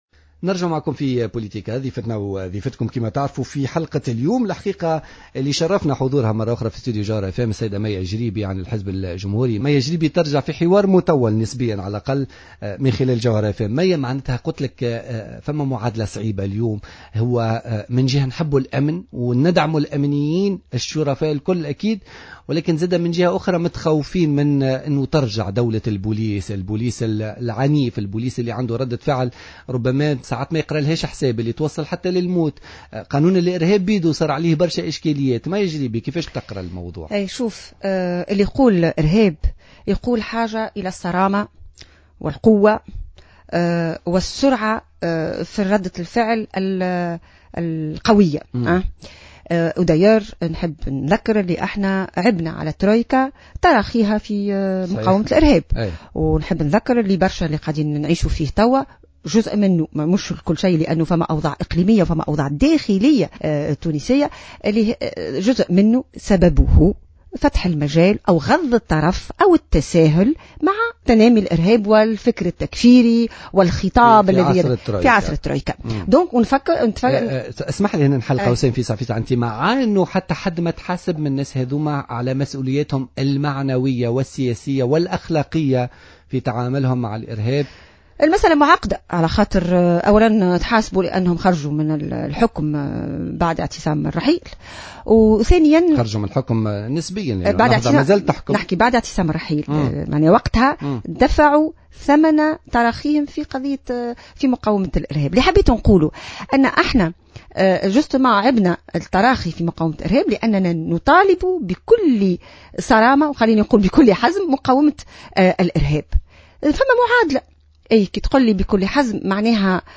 أكدت الأمينة العامة للحزب الجمهوري مية الجريبي ضيفة بوليتيكا اليوم الثلاثاء 4 اوت 2015 أن التخوف من عودة دولة البوليس من خلال قانون مكافحة الإرهاب مشروع في ظل التجاوزات التي تحدث .